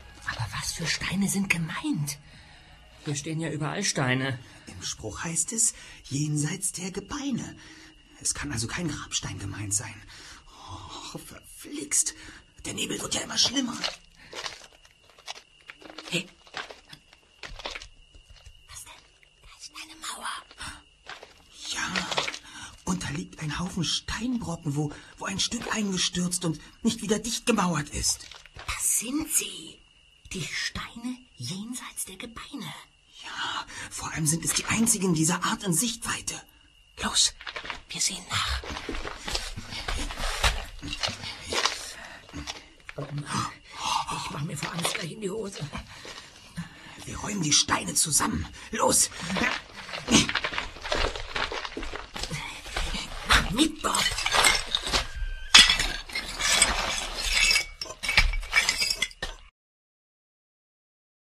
- Super-Papagei 2004 | Physical CD Audio drama